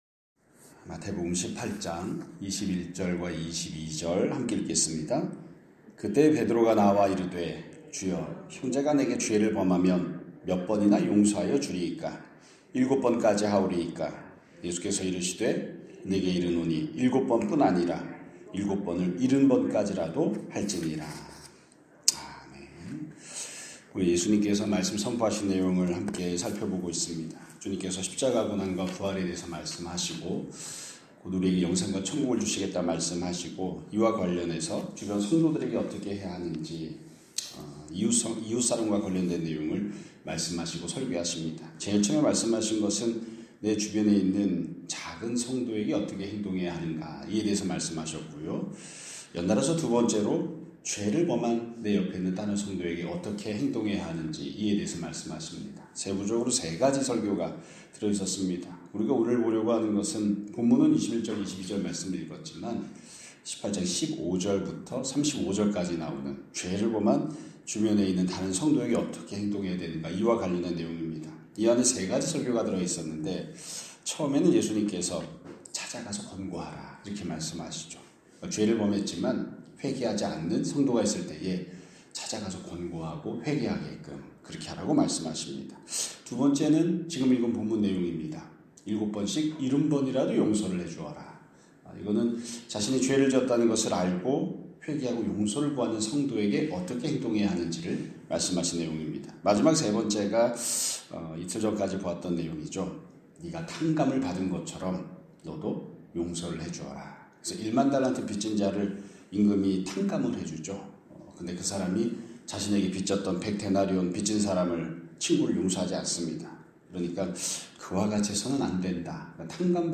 2025년 12월 26일 (금요일) <아침예배> 설교입니다.